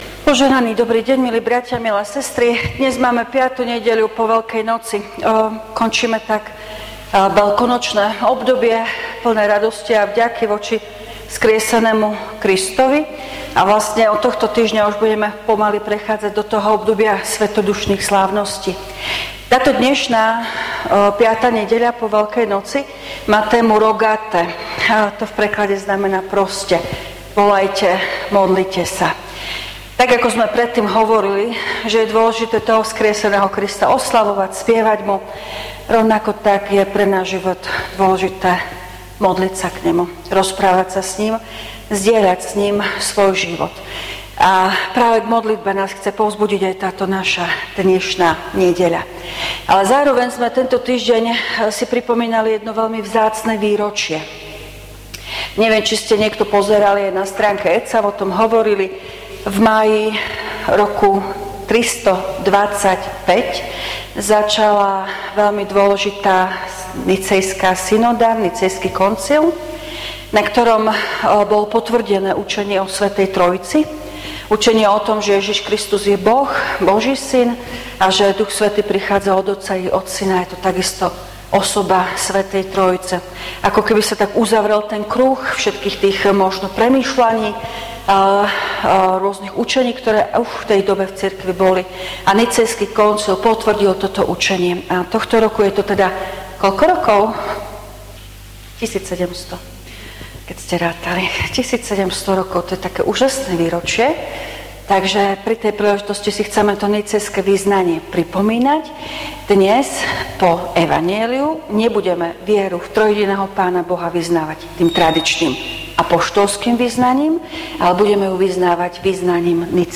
Služby Božie – 5. nedeľa po Veľkej noci
V nasledovnom článku si môžete vypočuť zvukový záznam zo služieb Božích – 5. nedeľa po Veľkej noci.
PIESNE: 195, 310, 655, 318, A89.